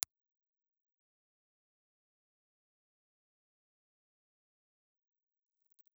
Condenser
Cardioid
Impulse Response file of the Sony C450 in "V" position.
Sony_C450_V_IR.wav